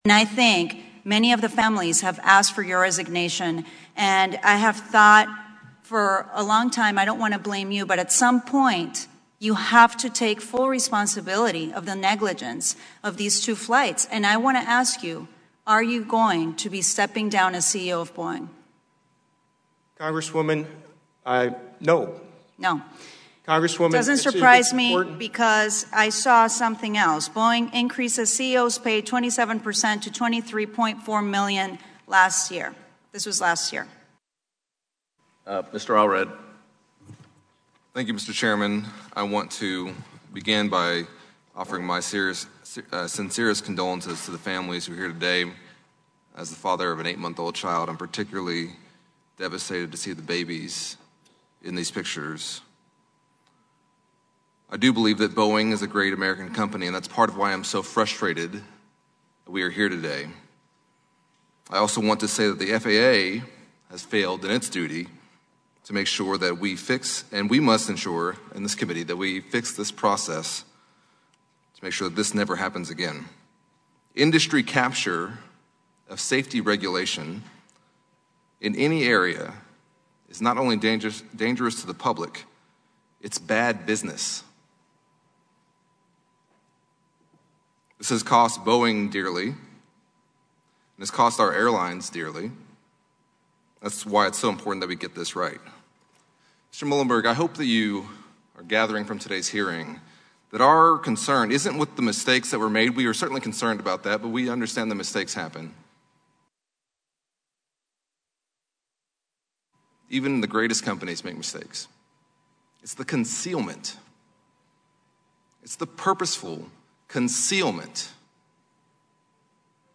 Washington (WBAP/KLIF) – The head of Boeing took a grilling from capitol hill lawmakers for a second straight day Wednesday.
Listen for Murcarsel-Powell and Colin Allred below: